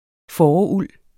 Udtale [ ˈfɒːɒ- ]